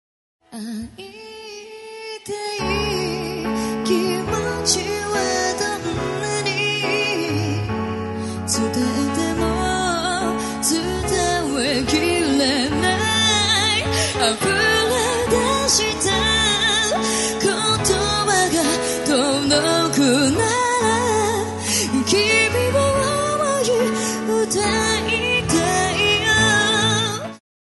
Live ver. In Taiwan